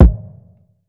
Crime Kick.wav